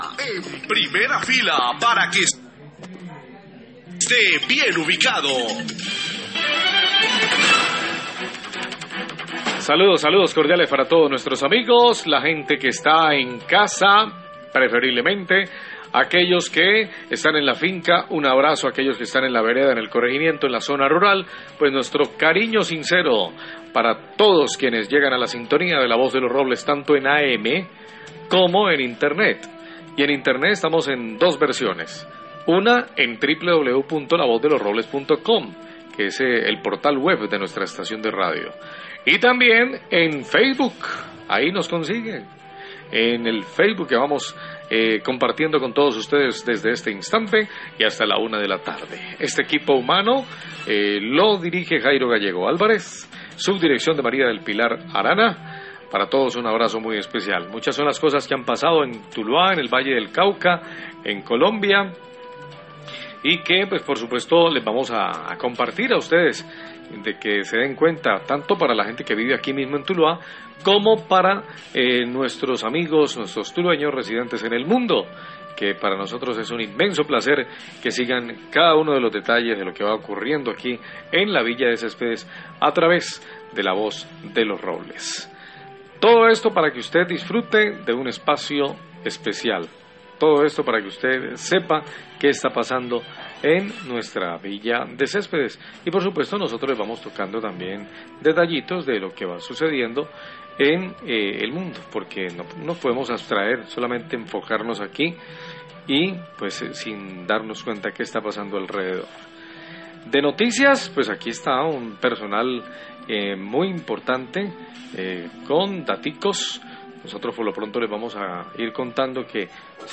Radio
Periodistas de La Voz de los Robles expresan su preocupación por la creciente confusión de los usuarios que desean pagar los servicios públicos y no saber como hacer, ni saben donde conseguir los recibos, esto teniendo en cuenta que por la emergencia los recibos no llegarán a las casas.